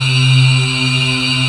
VACUUMING.wav